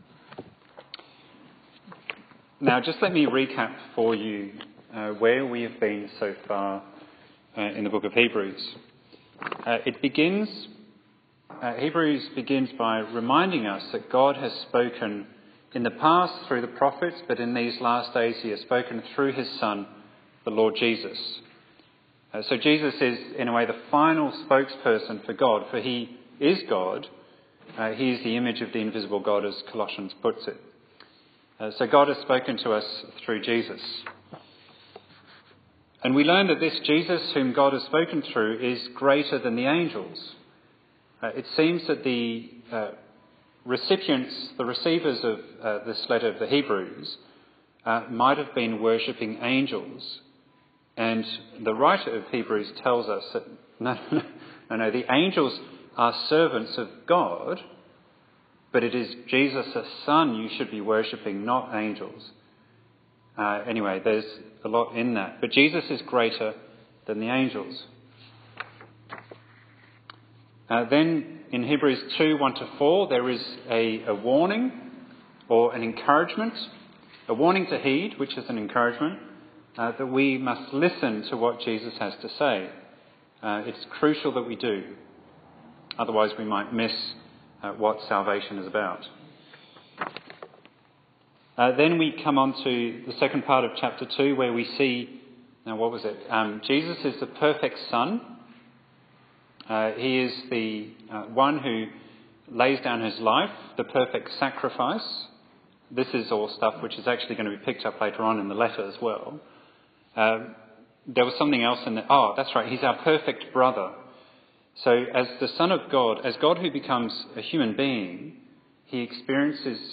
Bible Text: Hebrews 4:1–11 | Preacher